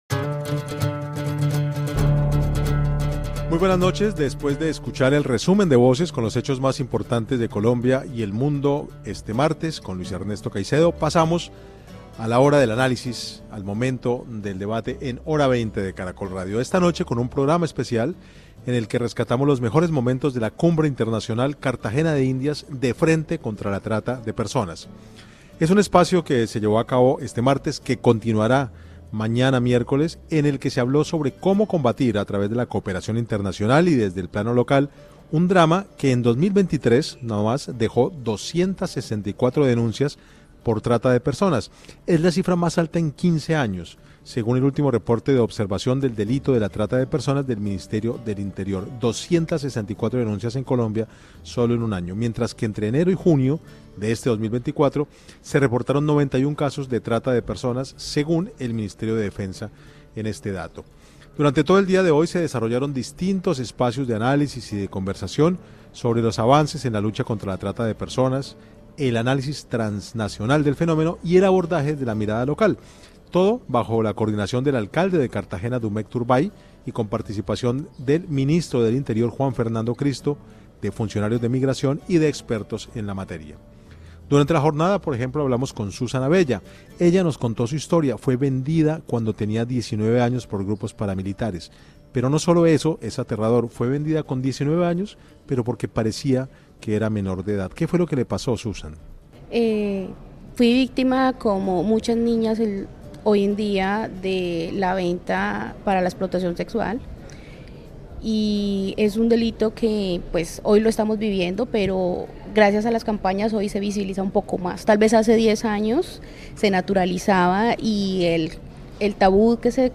Reviva los mejores momentos de la Cumbre Internacional: Cartagena de Indias de frente contra la trata de personas, en el que se analizó la realidad de un drama como la trata de personas en Colombia y a nivel internacional.